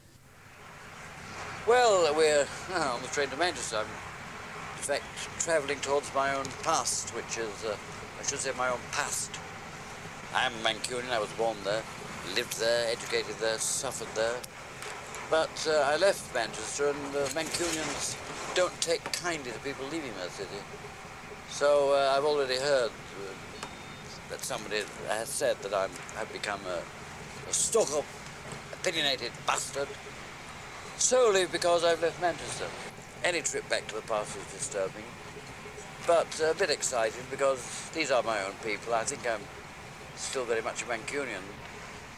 AB-on-the-train-to-Manchester-1989-from-Bookshelf.mp3